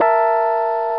E Piano Sound Effect
Download a high-quality e piano sound effect.
e-piano.mp3